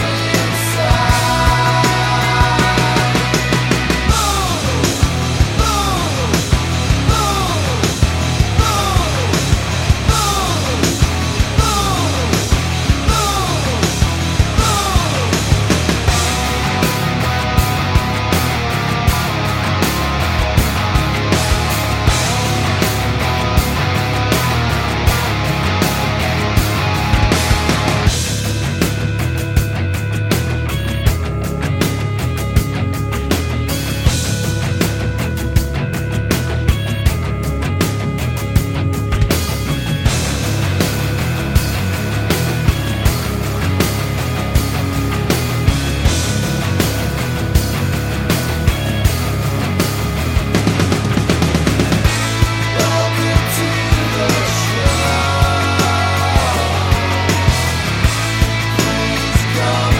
no Backing Vocals Rock 3:39 Buy £1.50